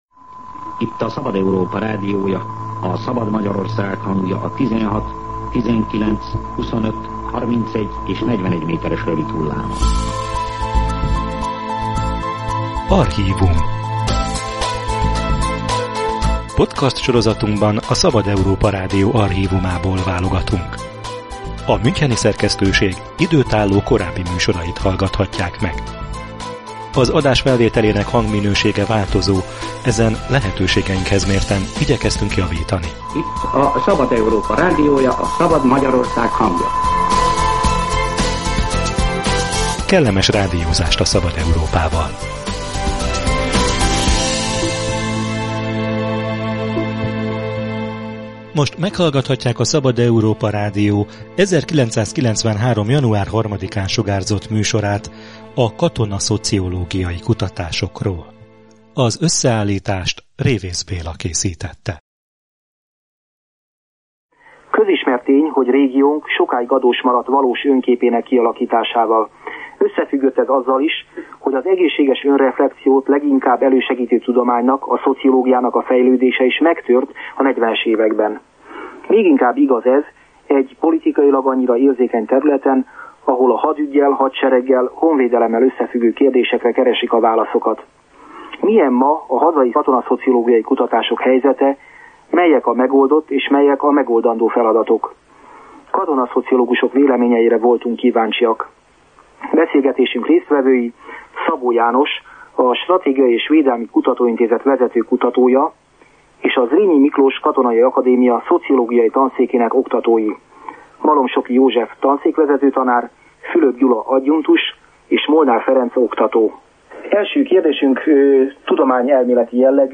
Jelentős átalakuláson ment át Magyarország katonai ereje az 1990-es évek elején. Ez kihívást jelentett a vezérkarnak és a kiskatonáknak is - ezért volt aktuális a Szabad Európa Rádió 1993. január 3-ai adása, melyben katonapszichológusokkal beszélgetnek az elitváltásról és a szakmai tapasztalatról.